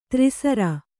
♪ tri sandhye